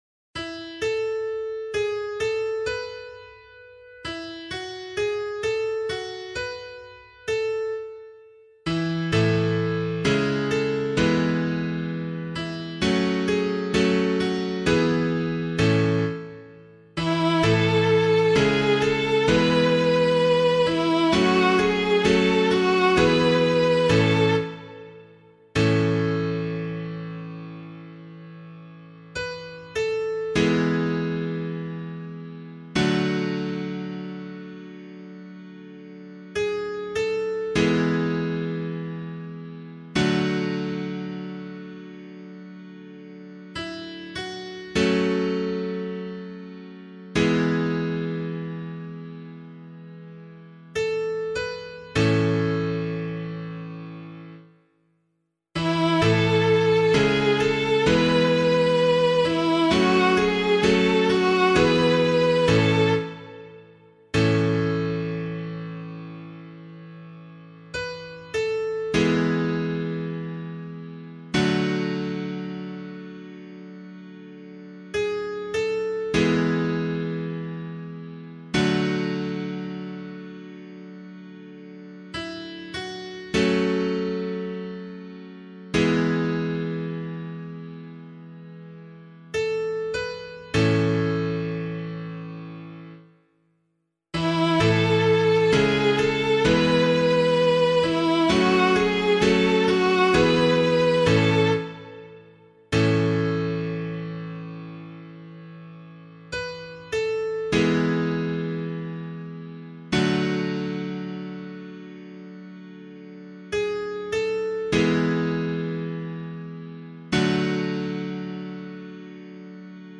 pianovocal